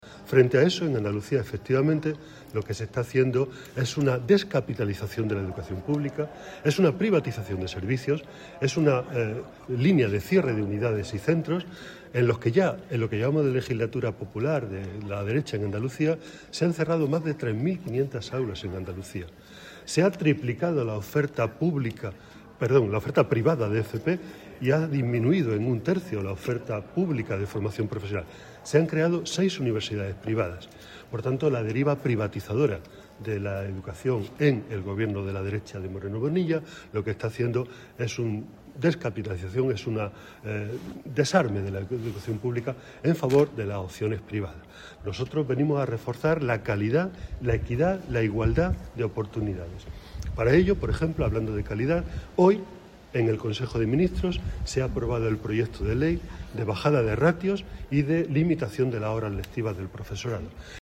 Abelardo de la Rosa, secretario de Estado de Educación, denunció hoy que Moreno Bonilla ha sumido a Andalucía en “una deriva privatizadora” que está suponiendo “un desarme y una descapitalización de la educación pública”. De la Rosa, que ha participado en un Foro sobre Educación organizado por el PSOE de Jaén en la ciudad de Úbeda, manifestó que la educación construye sociedad y que hay dos modelos: una educación “para unir” y otra “para segregar”.